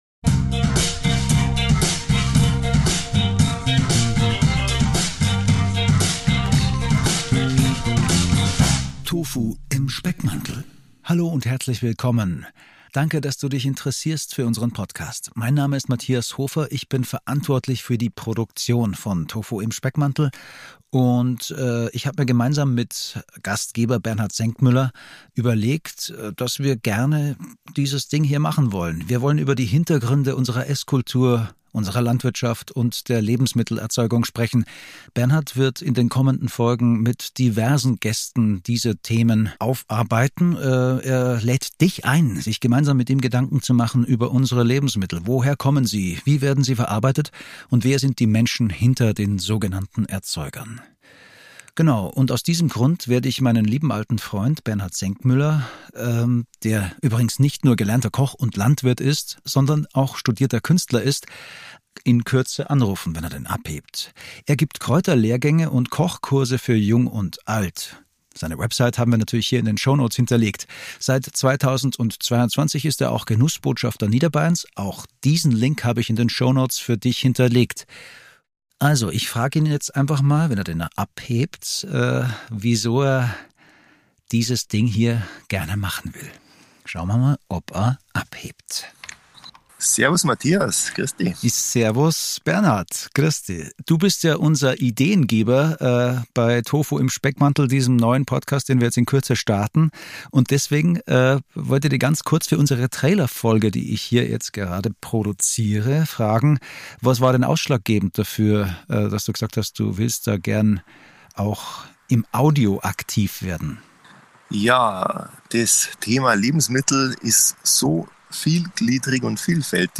Hier im Trailer stellen wir uns kurz vor.